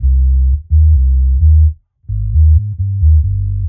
Index of /musicradar/dub-designer-samples/130bpm/Bass
DD_JBass_130_C.wav